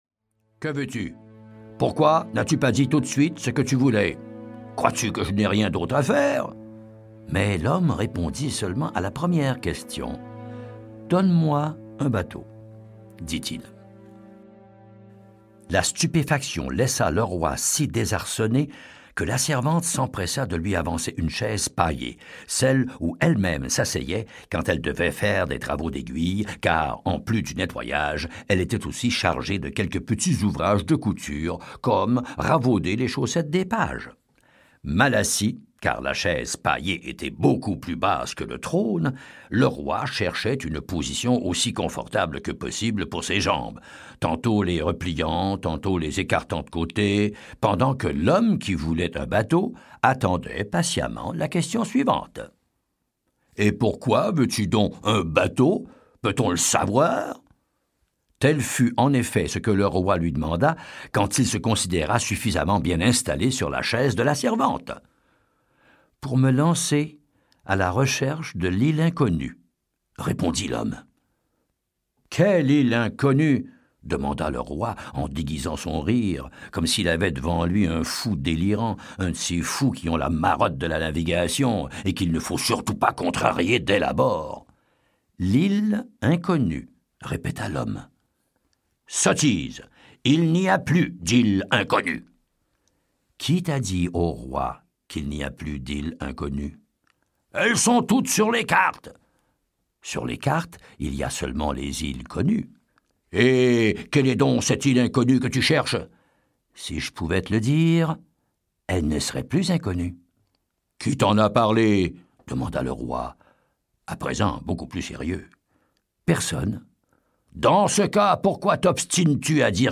Extrait gratuit - Le conte de l'île inconnue de Albert Millaire, José Saramago